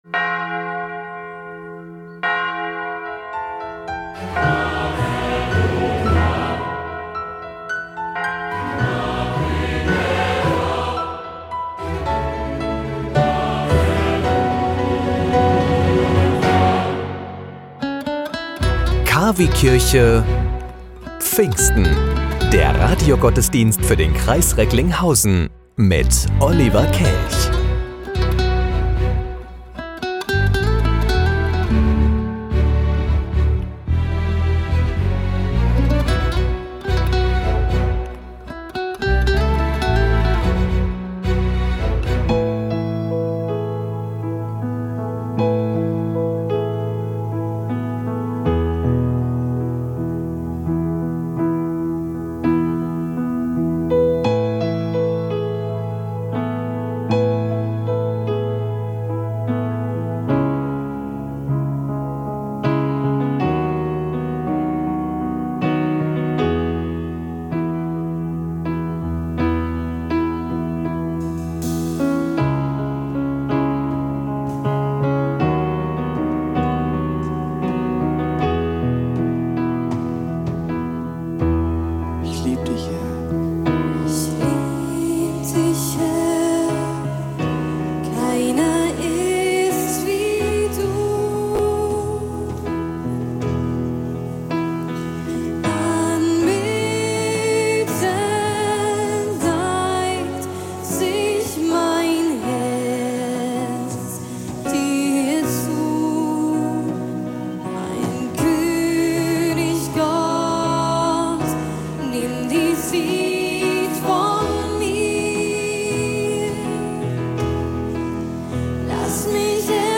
Radiogottesdienst zu Pfingsten
Im Radiogottesdienst feiern wir den Geburtstag.